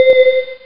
elevbell1.mp3